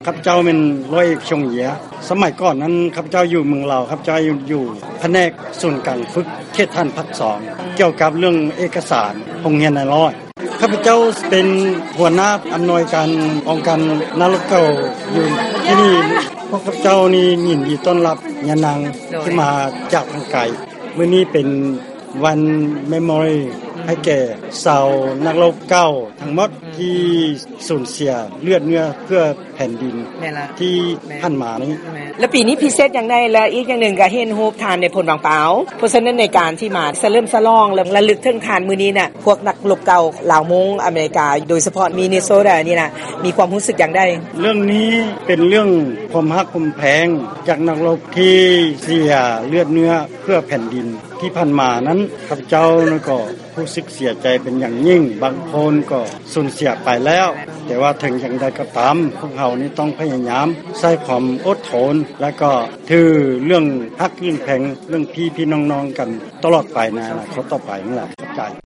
ການສຳພາດ